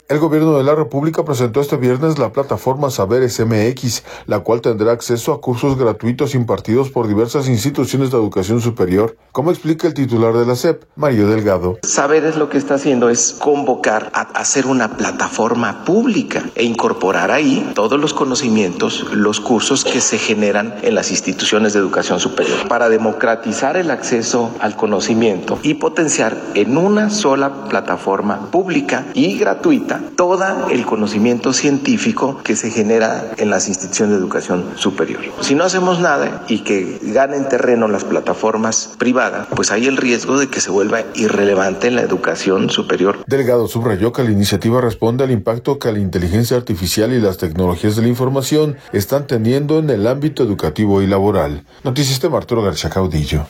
El Gobierno de la República presentó este viernes la plataforma Saberes Mx, la cual tendrá acceso a cursos gratuitos impartidos por diversas instituciones de educación superior, como explica el titular de la SEP, Mario Delgado.